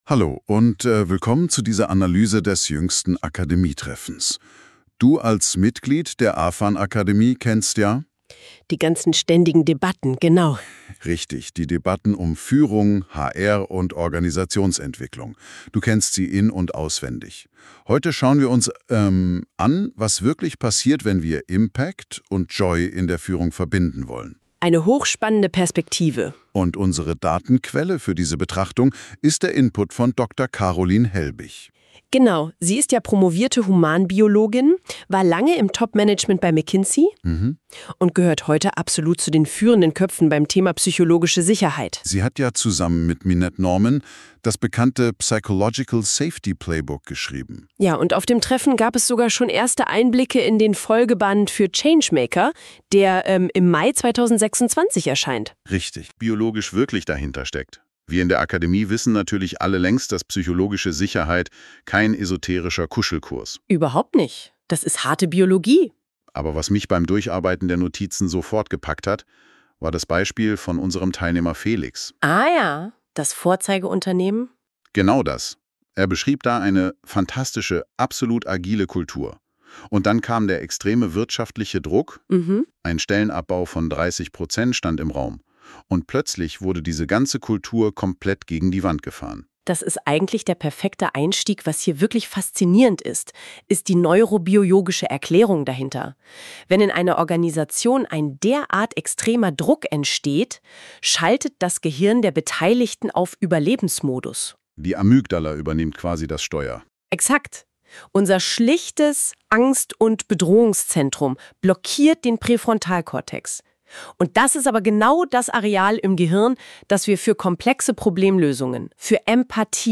Audiozusammenfassung
von NotebookLM | KI-generierter Inhalt